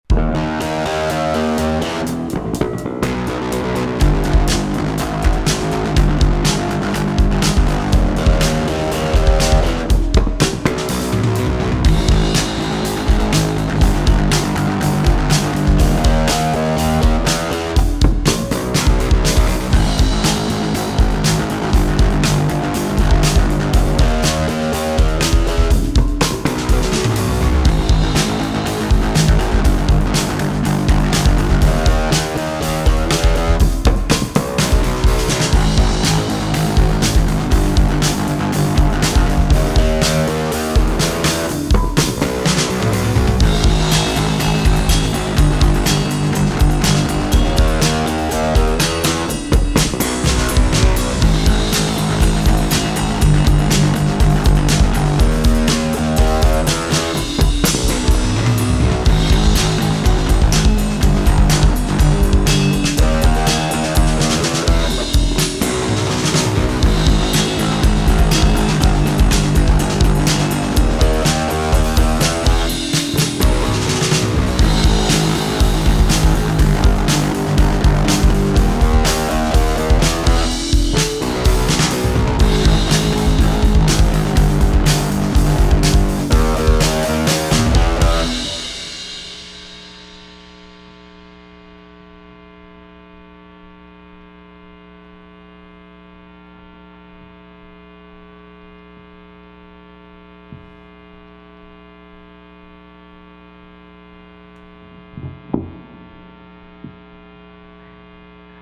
16th Note Nasty Bass Chuggalug